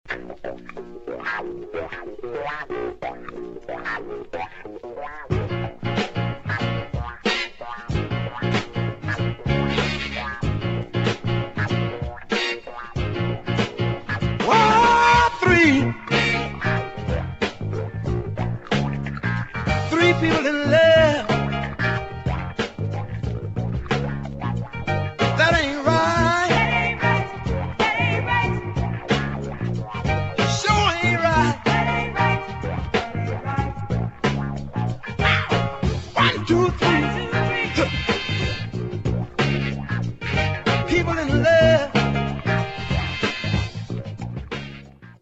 [ JAZZ / FUNK / DOWNBEAT ]